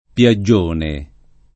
vai all'elenco alfabetico delle voci ingrandisci il carattere 100% rimpicciolisci il carattere stampa invia tramite posta elettronica codividi su Facebook piaggione [ p L a JJ1 ne ] s. m. — sim. il top. il P. (Tosc.)